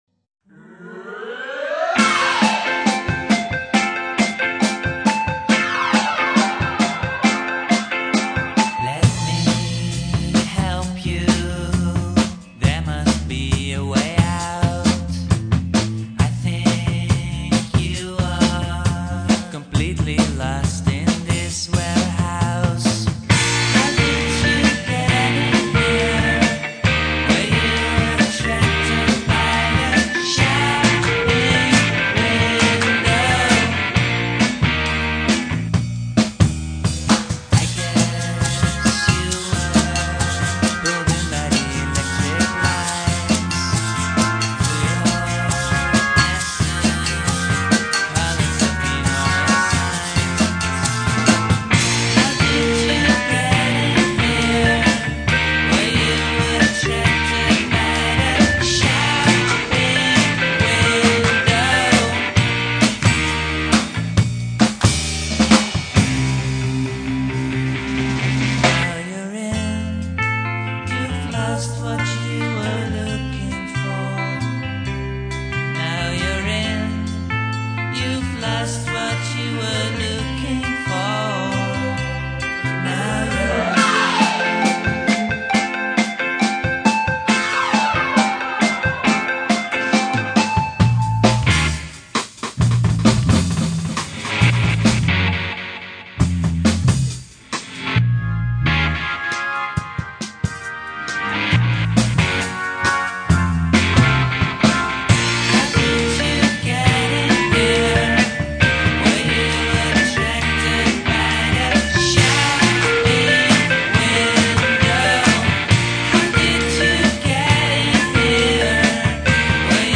where: Studio Aluna , Amsterdam
go dub in the break